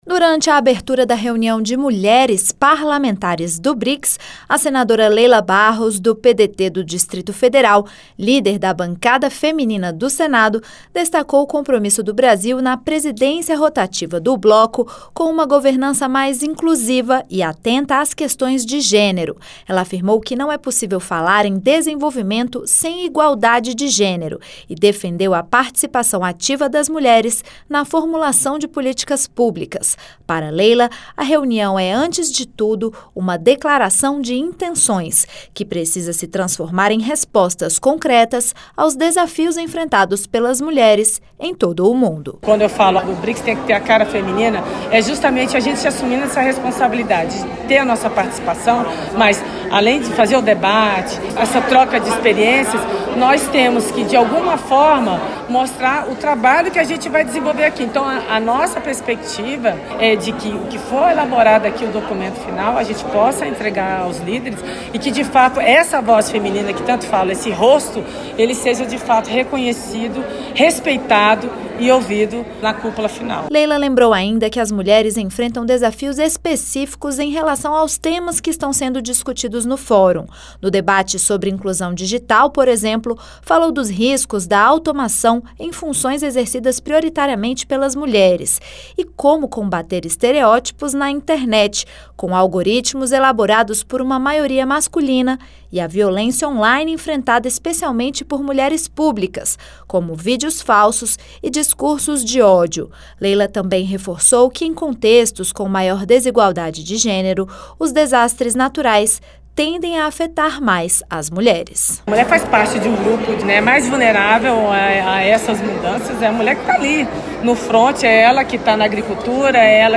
Na abertura da reunião de mulheres parlamentares do Brics, a senadora Leila Barros (PDT-DF) defendeu o protagonismo feminino nas decisões globais. Em sua fala, ela destacou a exclusão das mulheres na economia digital e os efeitos desproporcionais das mudanças climáticas sobre a população feminina.